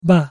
It would sound like "Bah," as in "Bah, bah, black sheep..."